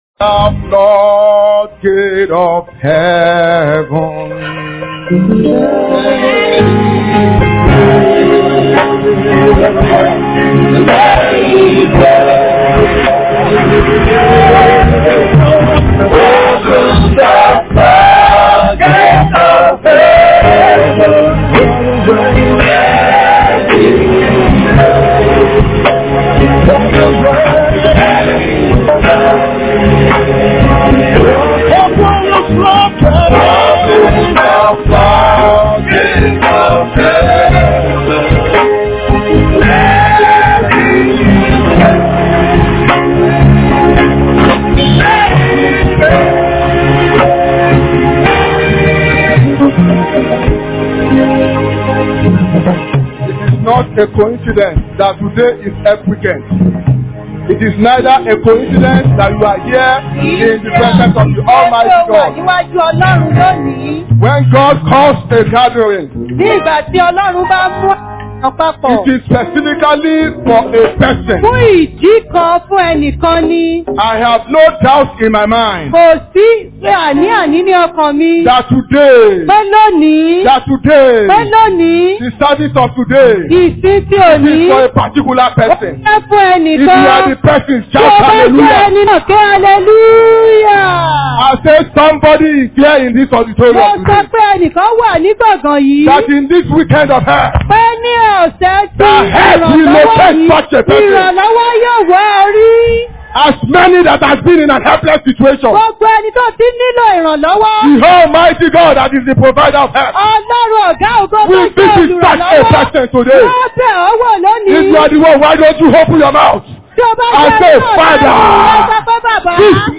Posted in Sunday Service